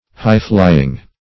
Highflying \High"fly`ing\, a.